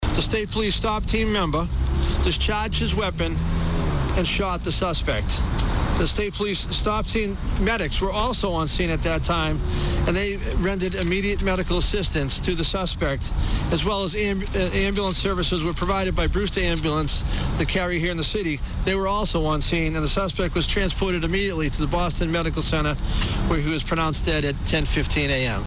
Norfolk County District Attorney Michael Morrissey: